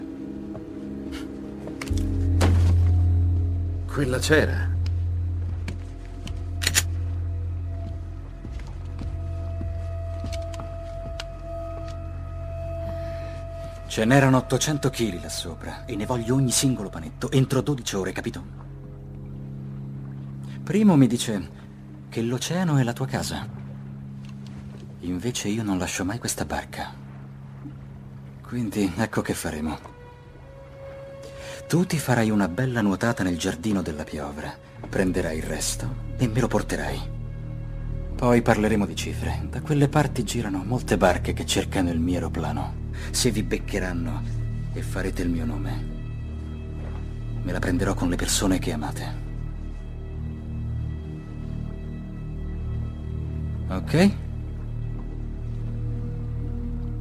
Il mondo dei doppiatori
voce di